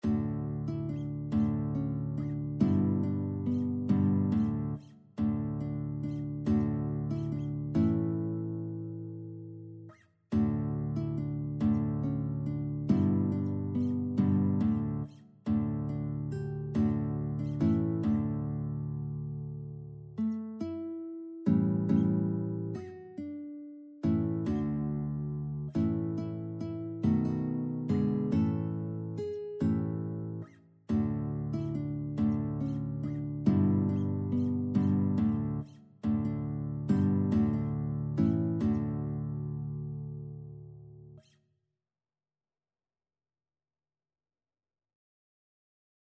Christian
6/8 (View more 6/8 Music)
Guitar  (View more Intermediate Guitar Music)
Classical (View more Classical Guitar Music)